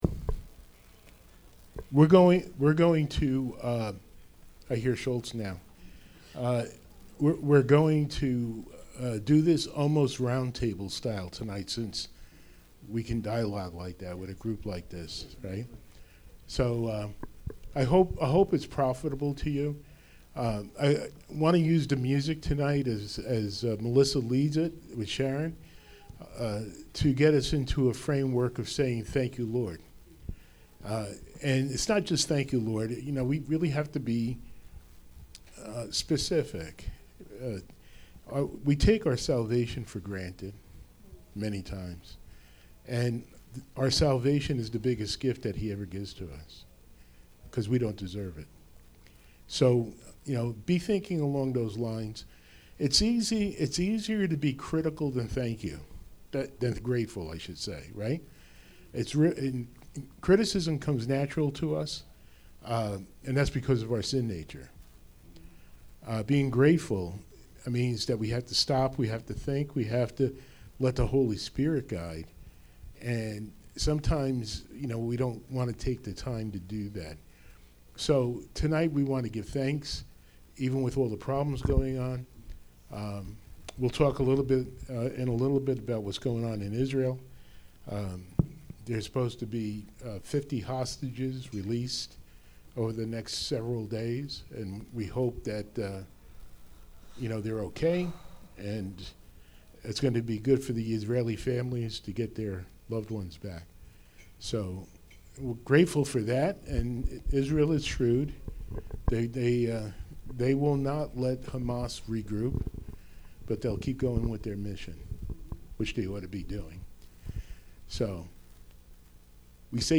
Service Type: Thanksgiving Eve Service